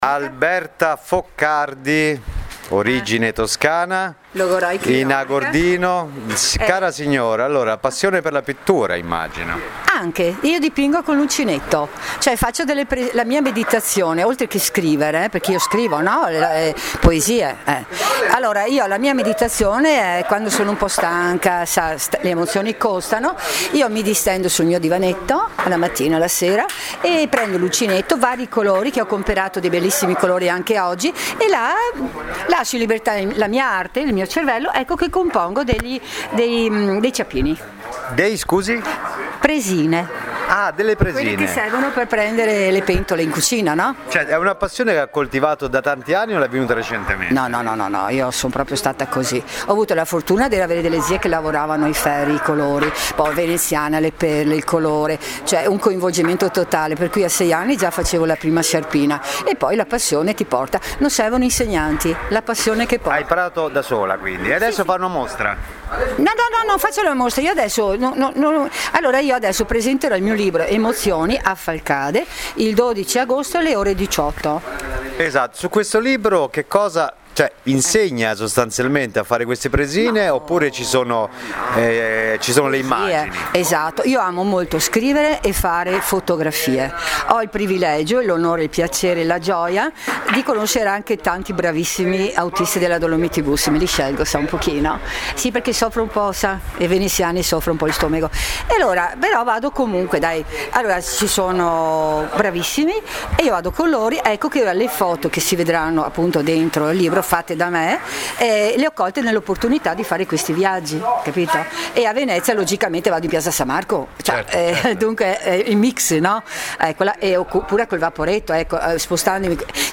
A colloquio con l’autrice